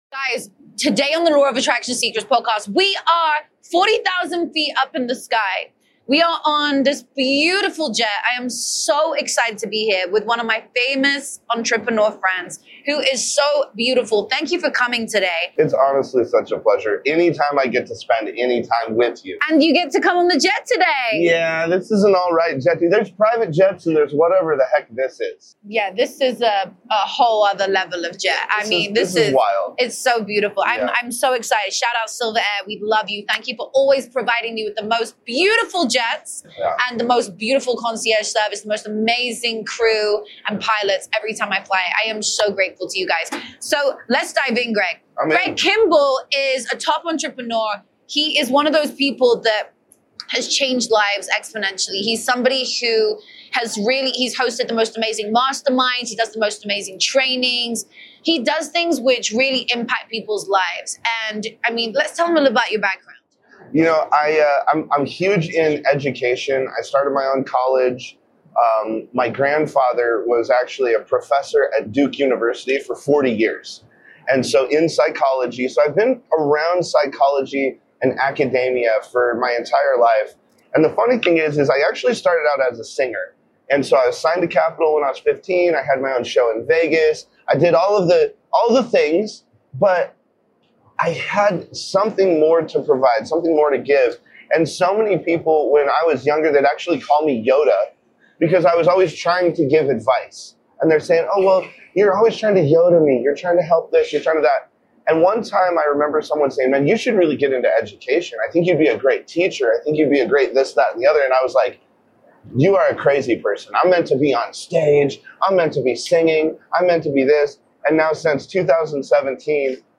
We’re 40,000 feet in the sky on a private jet… but this episode isn’t about luxury.